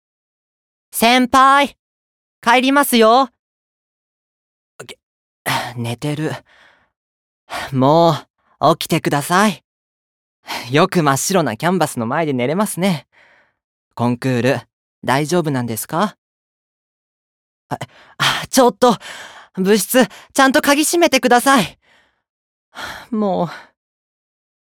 ボイスサンプル
世話好きの後輩